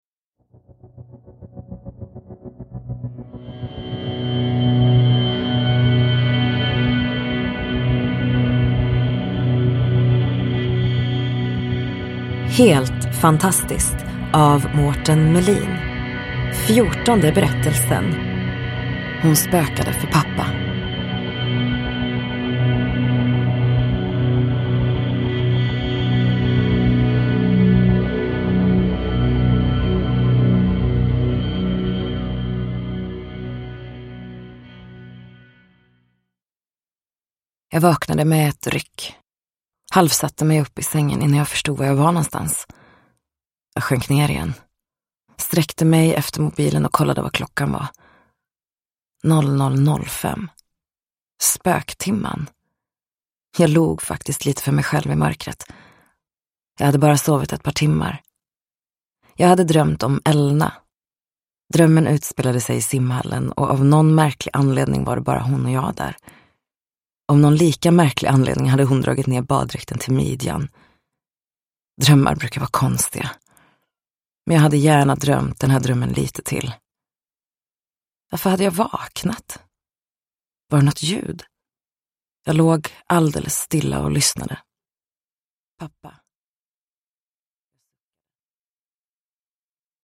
Hon spökade för pappa : en novell ur samlingen Helt fantastiskt – Ljudbok – Laddas ner